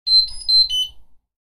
Electronic Beep Sound Effect
A short digital notification melody that alerts you something has happened.
Electronic-beep-sound-effect.mp3